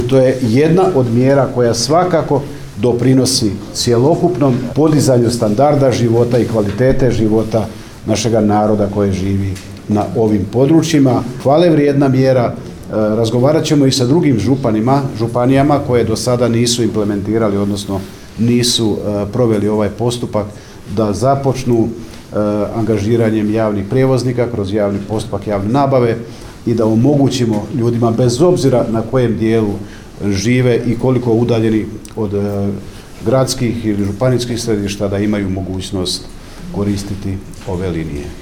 Značaj sufinanciranja javne usluge u cestovnom prijevozu potvrdio je i potpredsjednik Vlade, ministar hrvatskih branitelja Tomo Medved